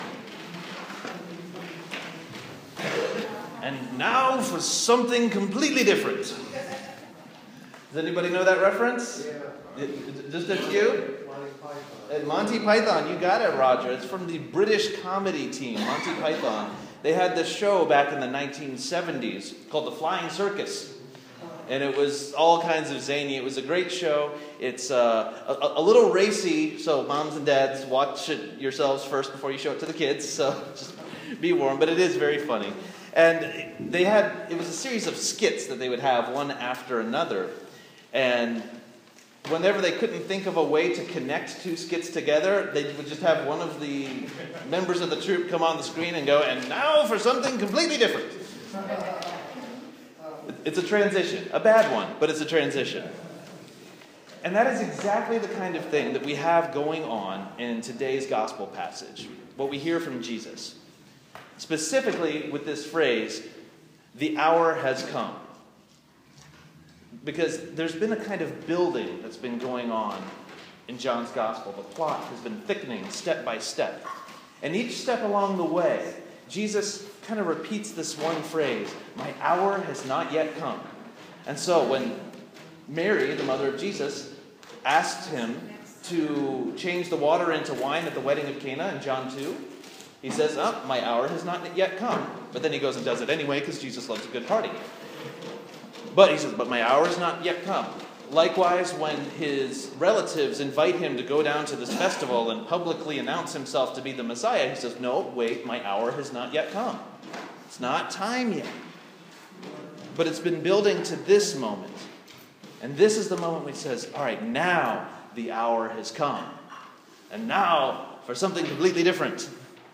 Sermon for the Fifth Sunday in Lent